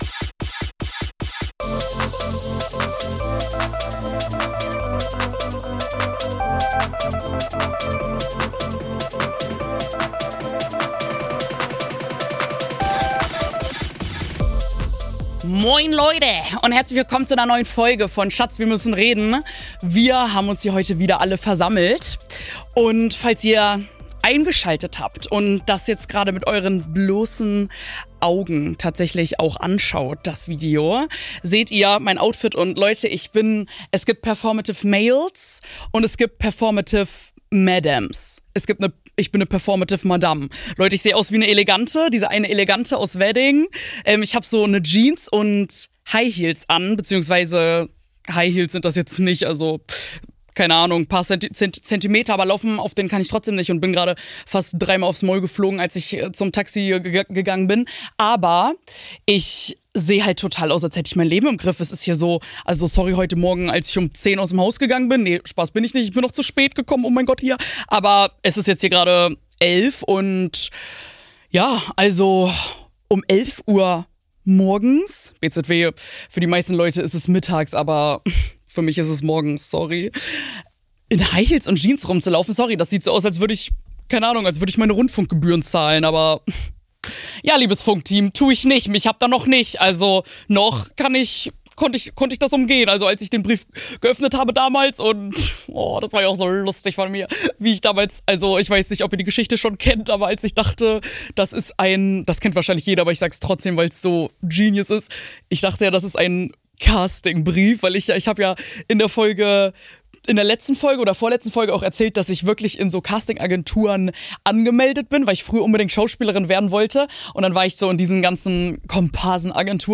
Denn heute ist der deutsche Justin Bieber aka Mike Singer zu Gast! Es geht ums fame sein und was alles komisches damit einhergeht, über seltsame Interviewfragen und DSDS.